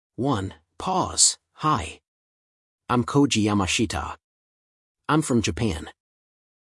Free AI Voice Generator and Text to Speech for Education Consultant